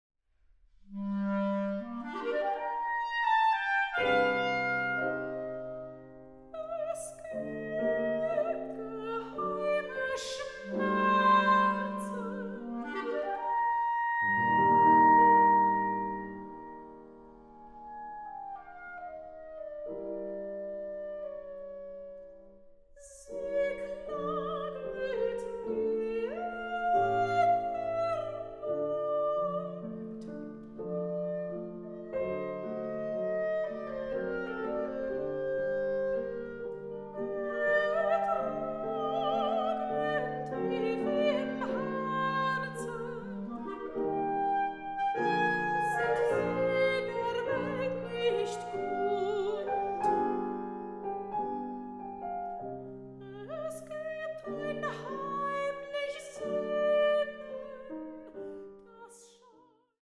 Klarinette
Klavier
Sopran
Aufnahme: Tonstudio Ölbergkirche, Berlin, 2023